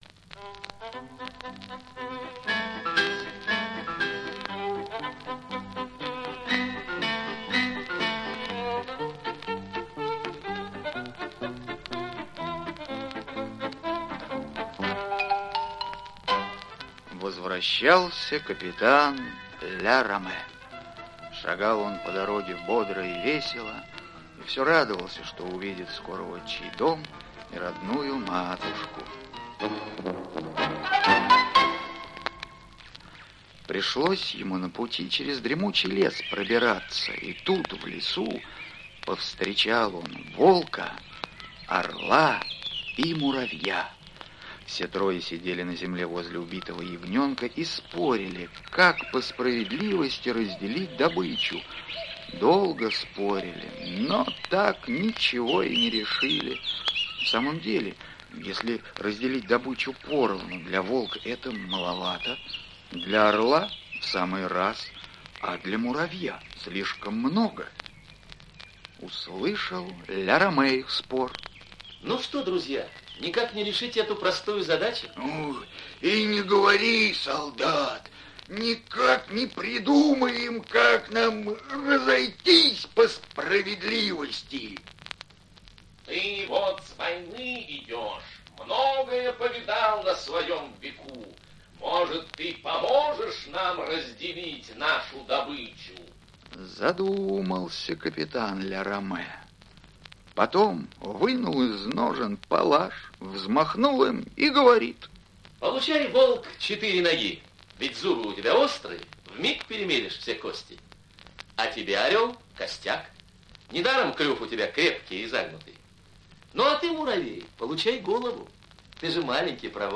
Возвращение Ля Раме - французская аудиосказка - слушать онлайн